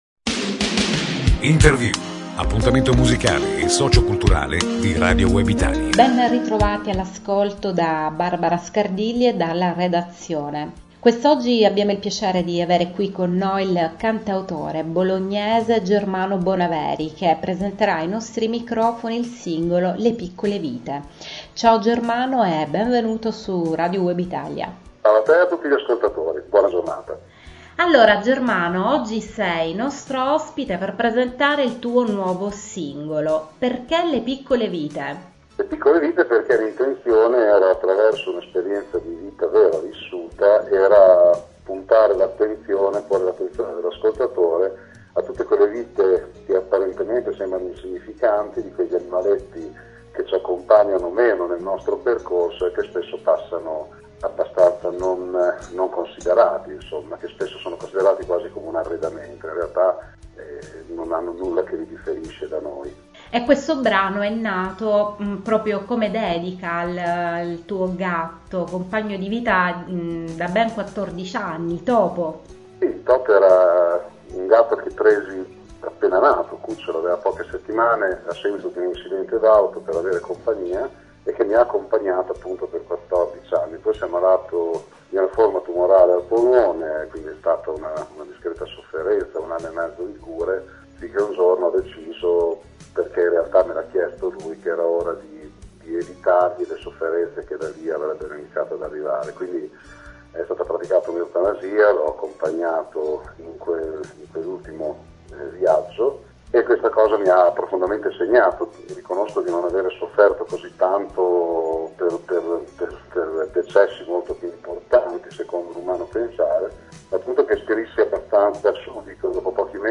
Intervista al cantautore bolognese